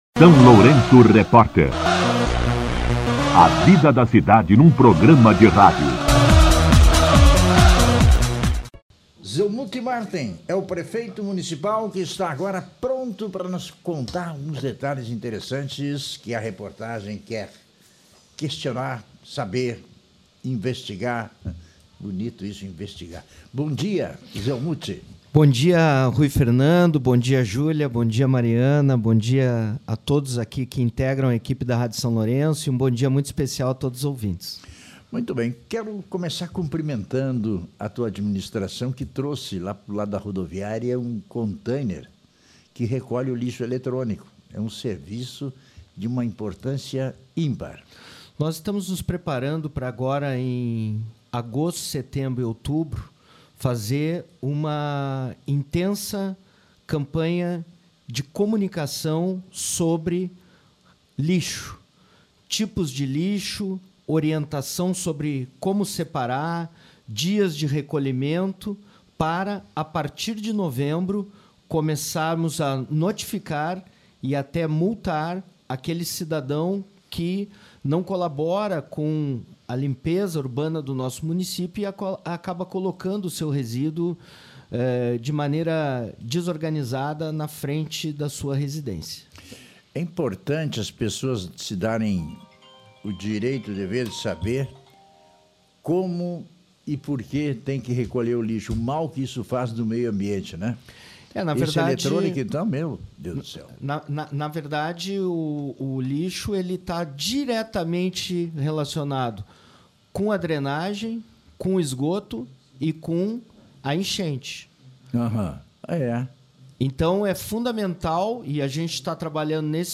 O prefeito Zelmute Marten (PT) concedeu entrevista ao SLR RÁDIO na manhã desta quinta-feira (7), destacando a regularização do pagamento dos salários dos funcionários da Santa Casa de Misericórdia de São Lourenço do Sul.